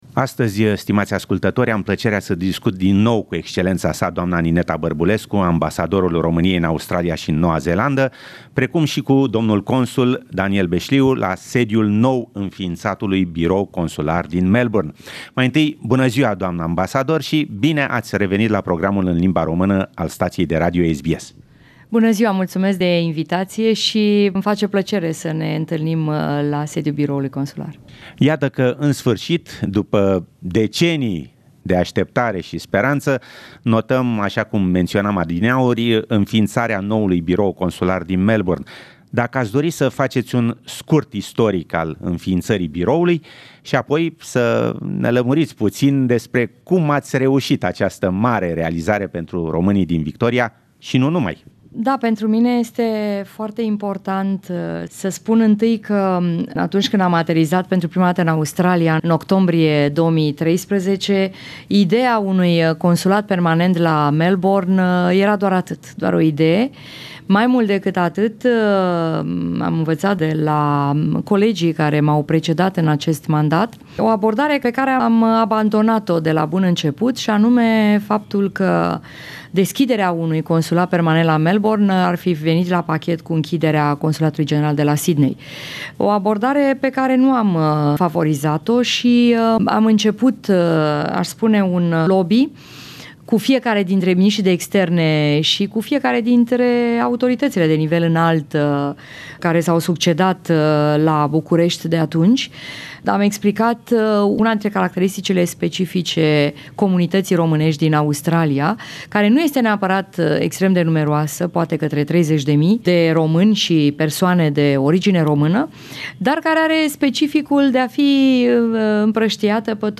Interview with Her Excelency Nineta Barbulescu, Romanian Ambassador in Australia and New Zealand and the Consul Daniel Besliu, at the new Romanian Consular Bureau, in Melbourne - pt.1
interview_consular_bureau_melbourne_pt_1_fin_-_12.30.mp3